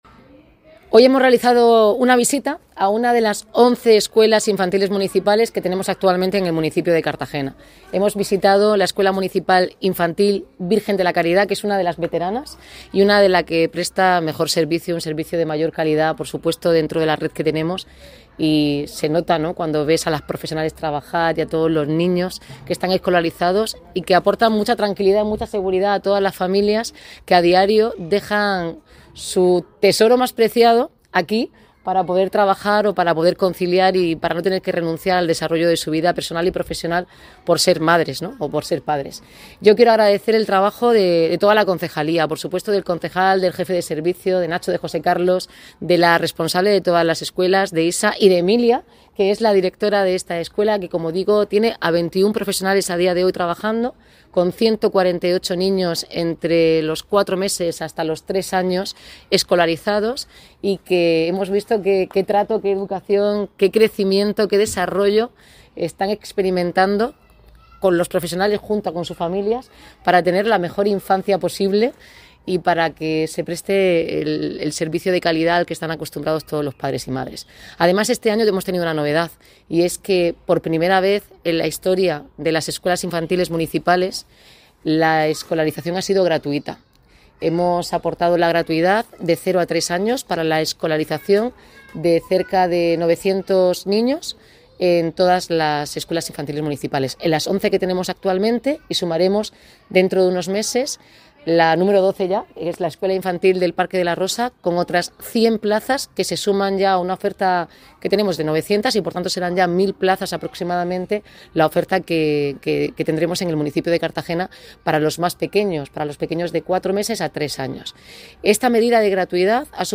Visita de la alcaldesa a la Escuela Infantil Municipal Virgen de la Caridad